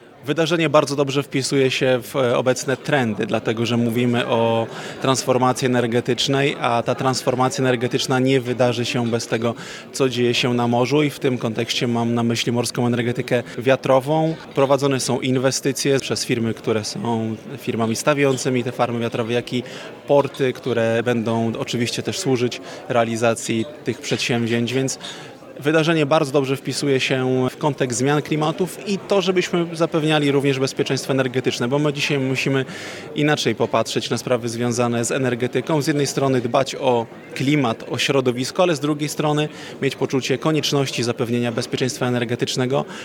Ważnym elementem energetyki odnawialnej są morskie farmy wiatrowe, o których mówi Arkadiusz Marchewka, wiceminister infrastruktury.